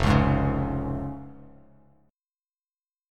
Fm#5 chord